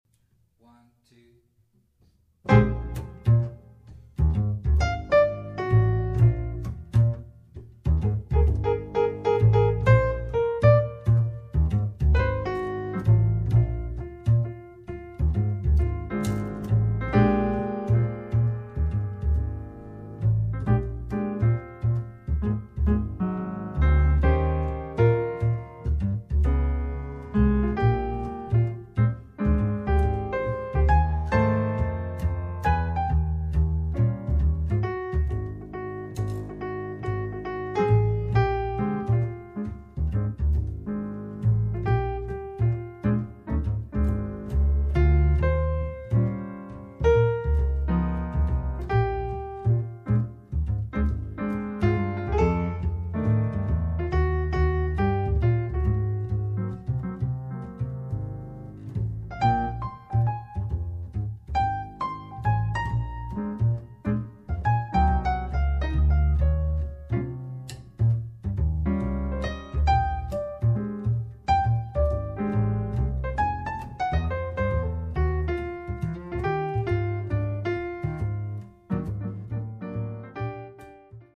Piano and Bass